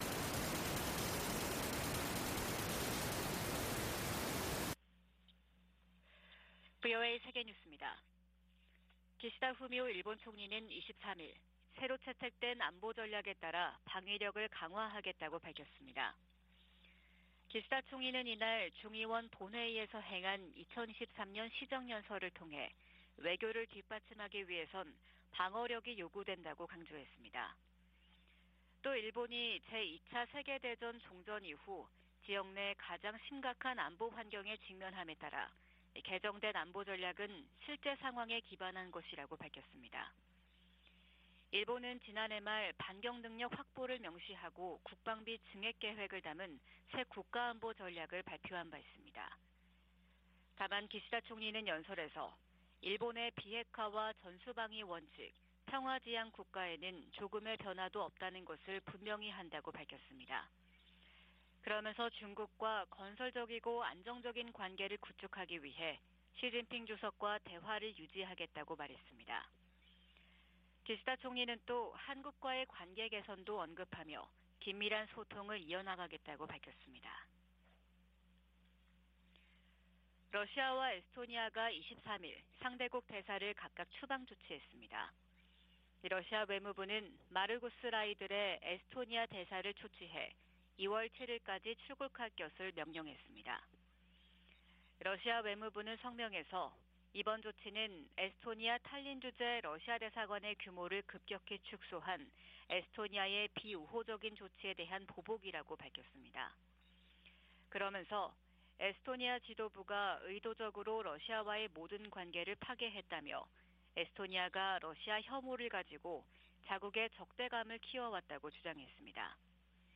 VOA 한국어 '출발 뉴스 쇼', 2023년 1월 24일 방송입니다. 백악관이 북한과 러시아 용병그룹 간 무기 거래를 중단할 것을 촉구하고, 유엔 안보리 차원의 조치도 모색할 것이라고 밝혔습니다. 미 태평양공군은 한국 공군과 정기적으로 훈련을 하고 있으며, 인도태평양의 모든 동맹, 파트너와 훈련할 새로운 기회를 찾고 있다는 점도 강조했습니다.